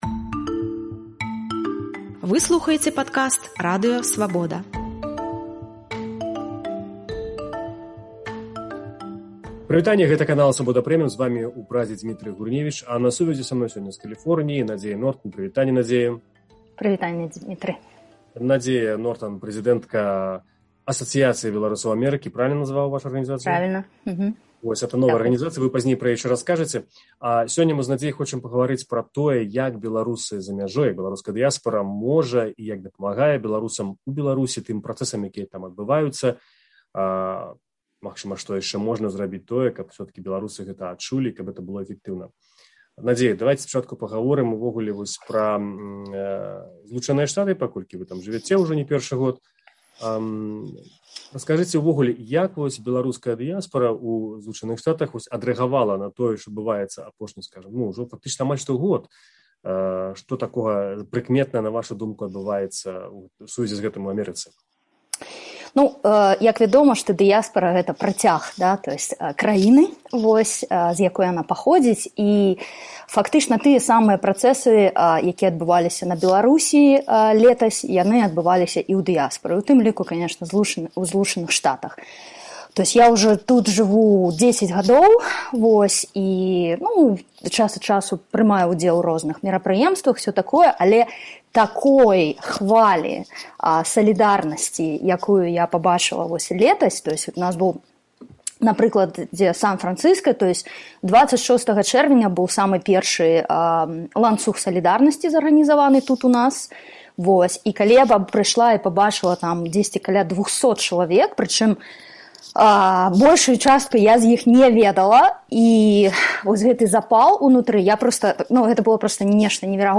У інтэрвію «Свабодзе» яна расказала, як падзеі на радзіме аб’ядналі амэрыканскіх беларусаў, як дыяспара дапамагае землякам у Беларусі і як лабіруе беларускае пытаньне ў ЗША.